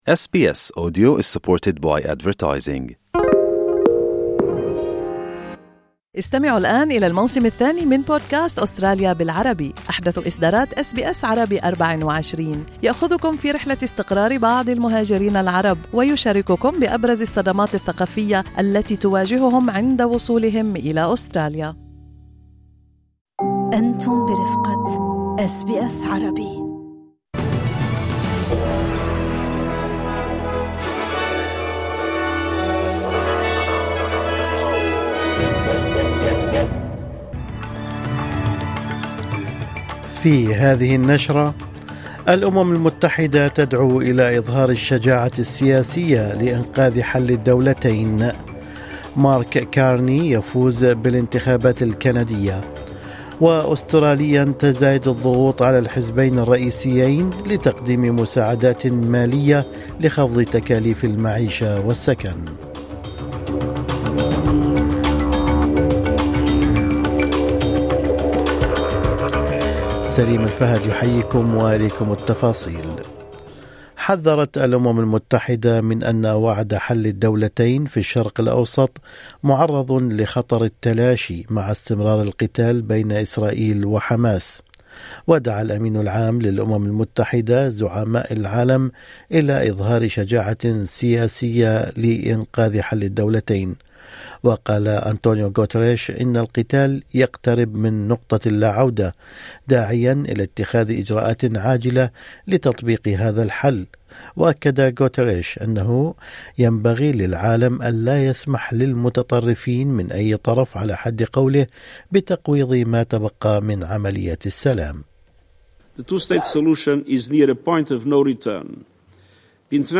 نشرة أخبار الصباح 30/4/2025